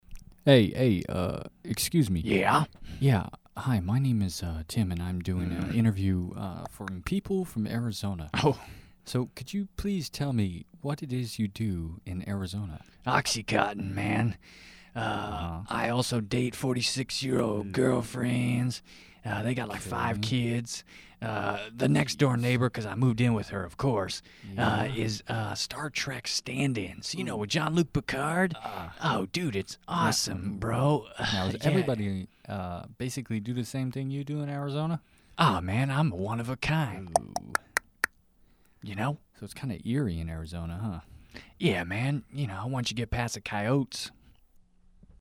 Eighties/synthpop